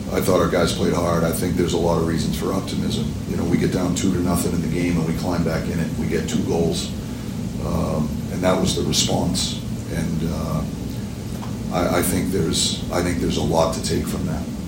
Coach Mike Sullivan said the Penguins fought hard despite the slow start.